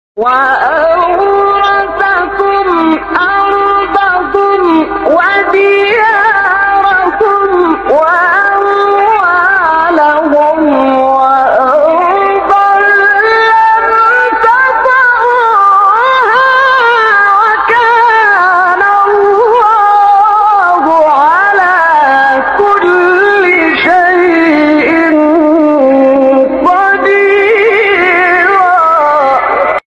سوره : احزاب آیه: 27 استاد : شعبان صیاد مقام : رست وَأَوْرَثَكُمْ أَرْضَهُمْ وَدِيَارَهُمْ وَأَمْوَالَهُمْ وَأَرْضًا لَّمْ تَطَئُوهَا ۚ وَكَانَ اللَّهُ عَلَىٰ كُلِّ شَيْءٍ قَدِيرًا ﴿٢٧﴾ قبلی بعدی